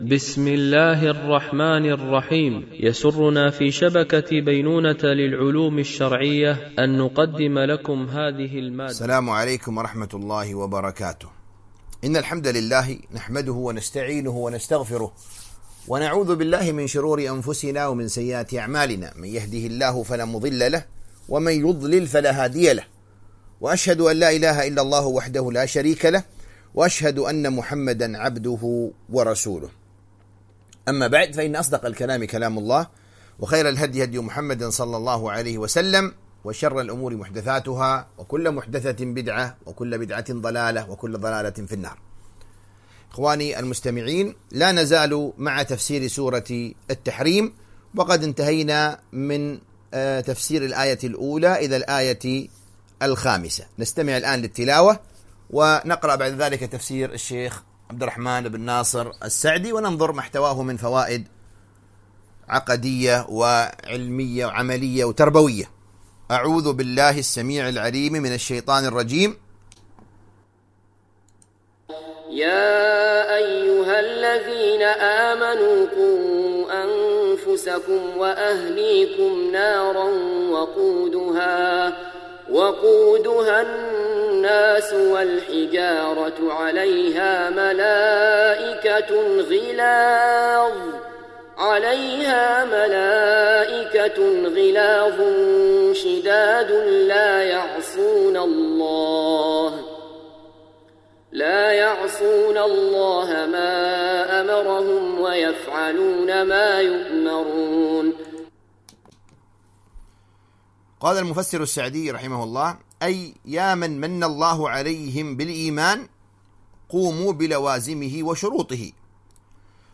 القسم: التفسير